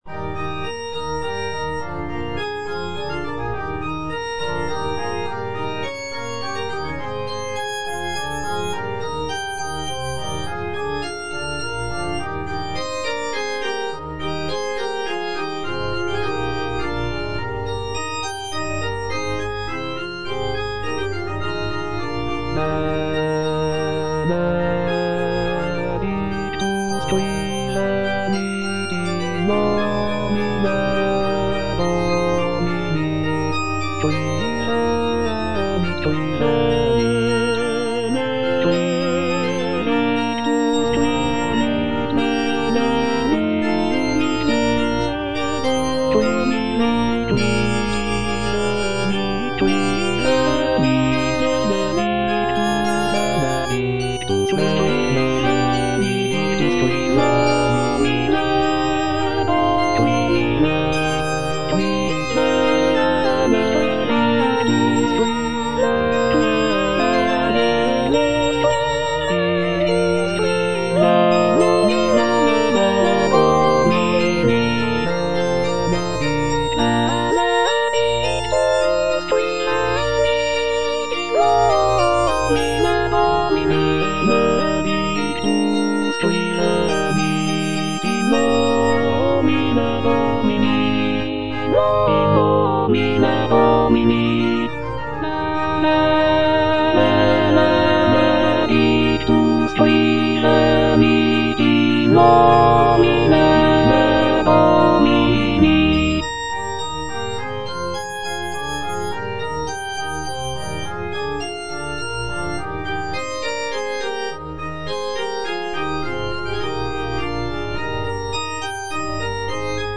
Alto (Emphasised voice and other voices) Ads stop
is a choral composition